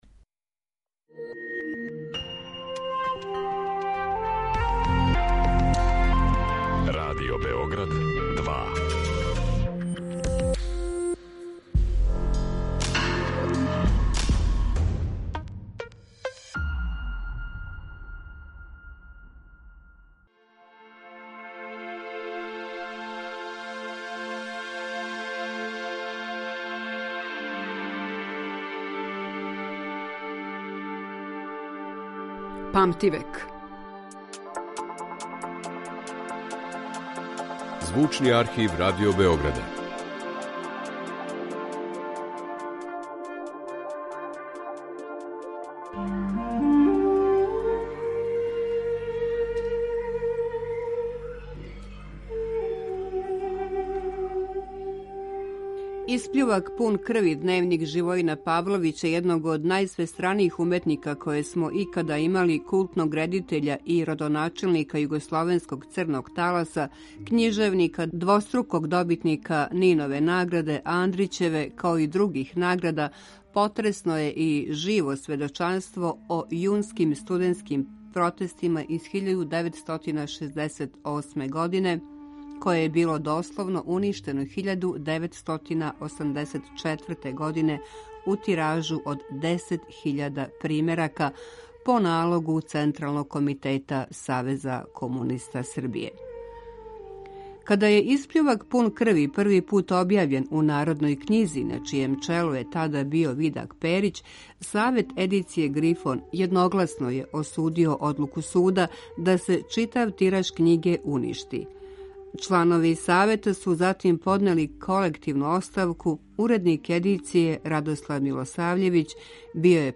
Слушамо тонски запис из 1990 године. Са Живојином Павловићем разговарао је новинар Драган Бабић.